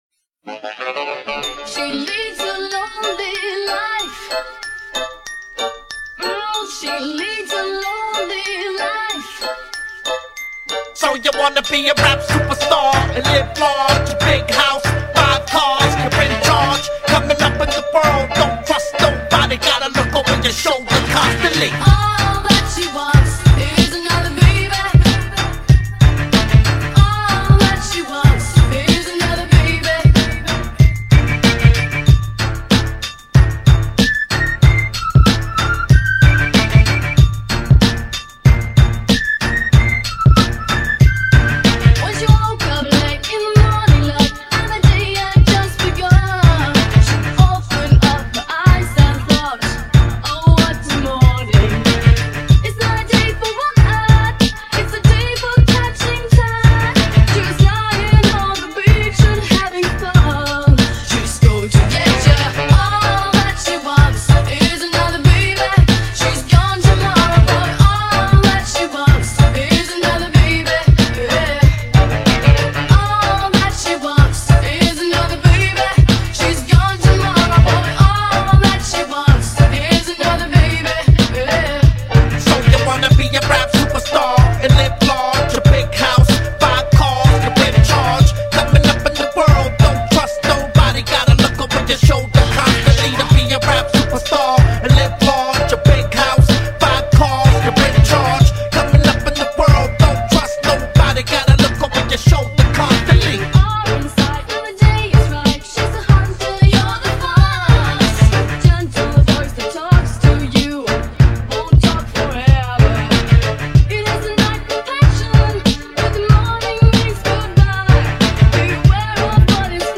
Bootlegs (page 3):